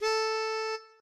melodica_a.ogg